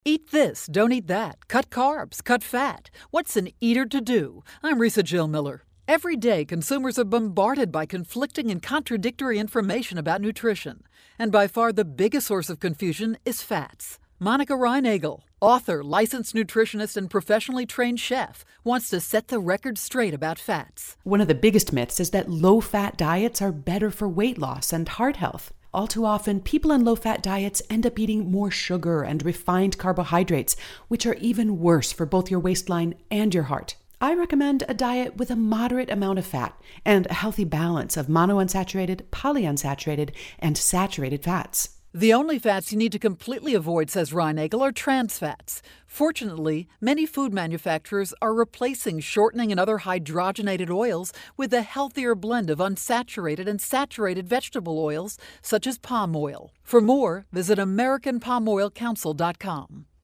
May 17, 2012Posted in: Audio News Release